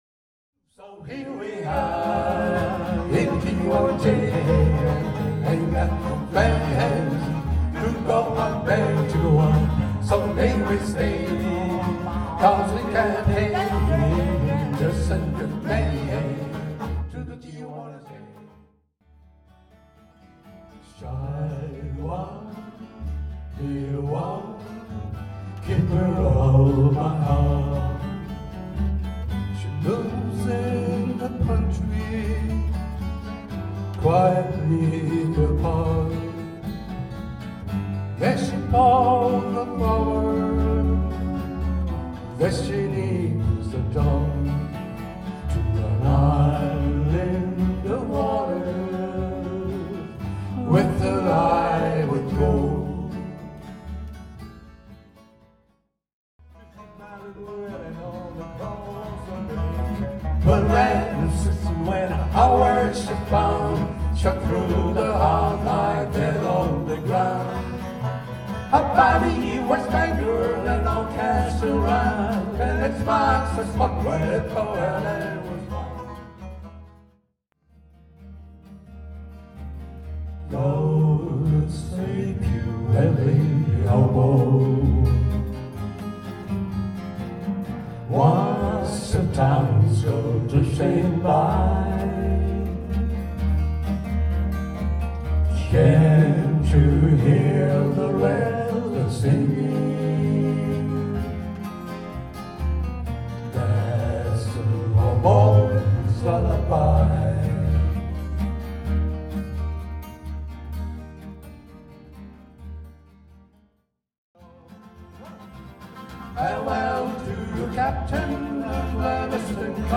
ブラフォー大会が終わったばかりだし、まあいいか、と本番へ向けて余裕のつもりが、なぜか不安を感じたのか急遽スタジオを借りて練習。
久々のスタジオの雰囲気はやっぱしいいですねえ。